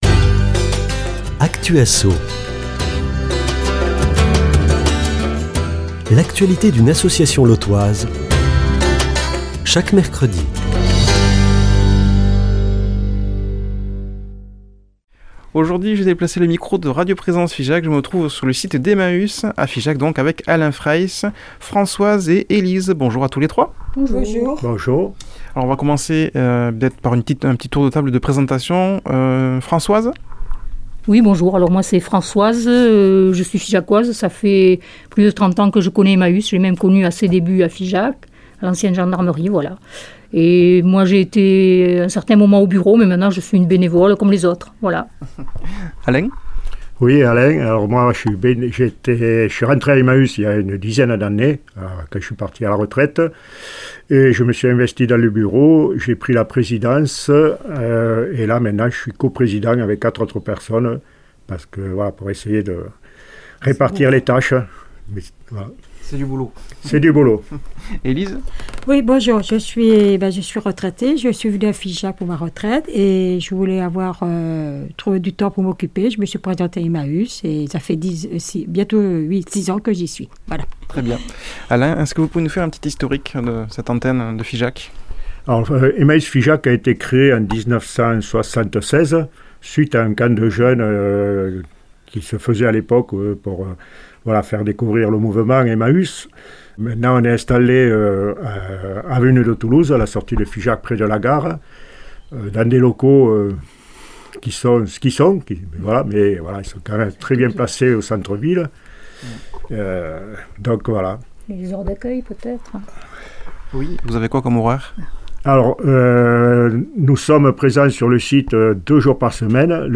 a déplacé le micro de Radio Présence Figeac pas très loin des studios.